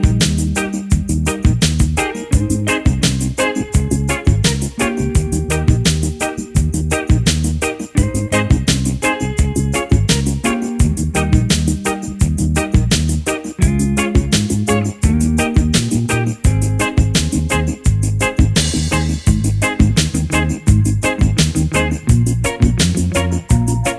no Backing Vocals Reggae 3:59 Buy £1.50